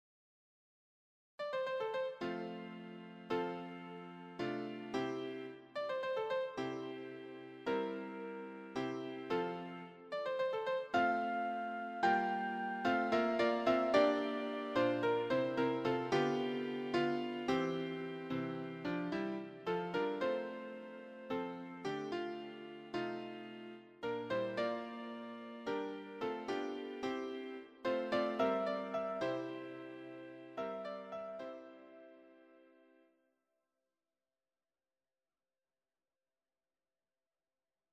Electronically Generated
choir SATB